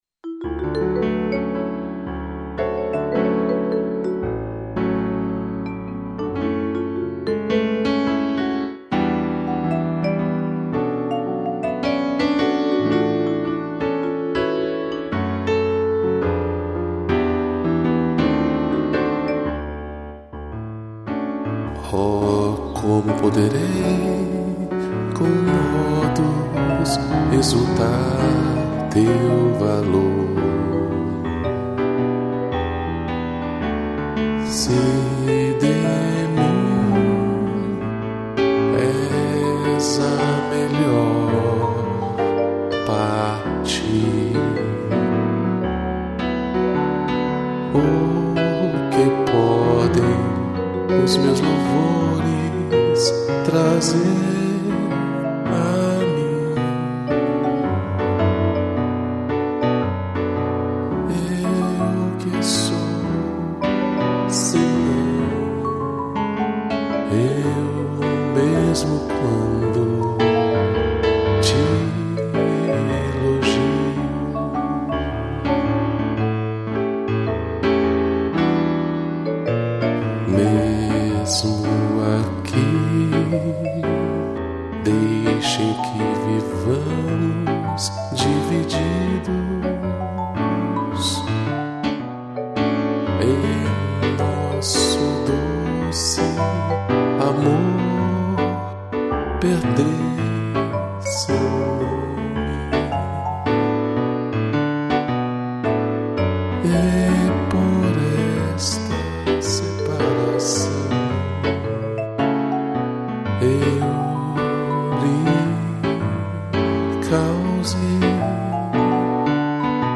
piano, vibrafone e sax